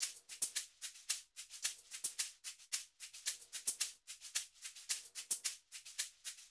WVD SHAKER 1.wav